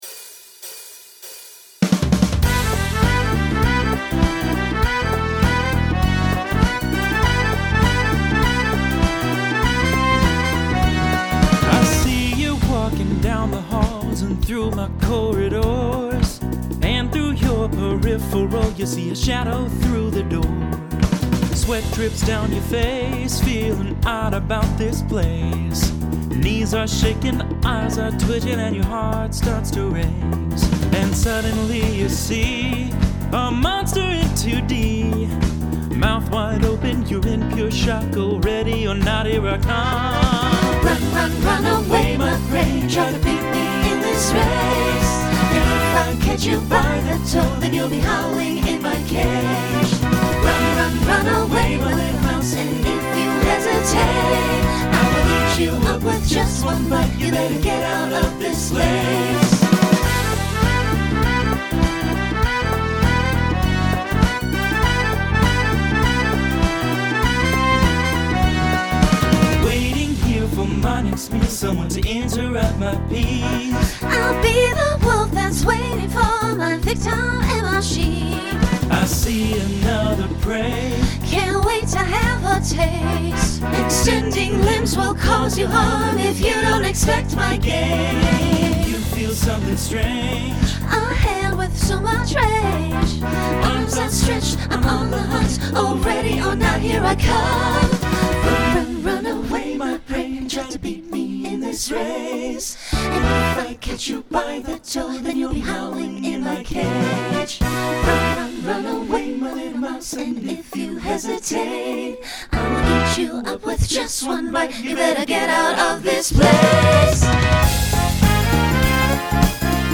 Swing/Jazz Instrumental combo
Story/Theme Voicing SATB